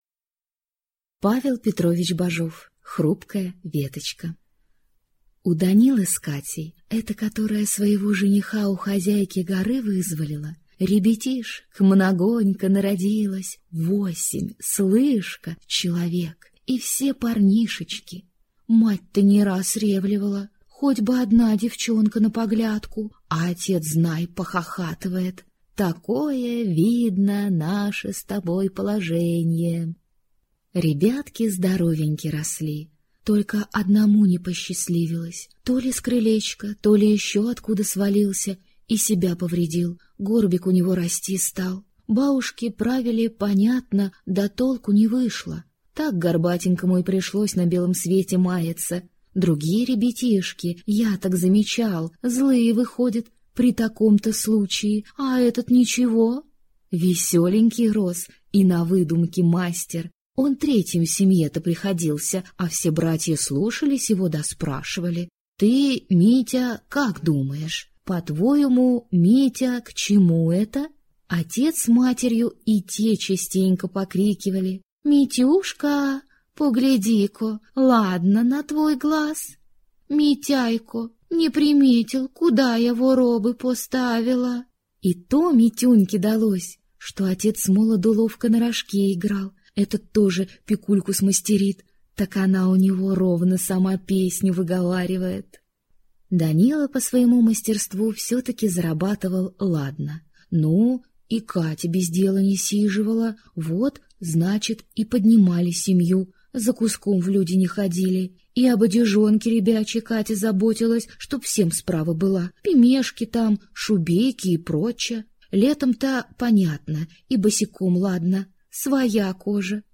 Аудиокнига Хрупкая веточка | Библиотека аудиокниг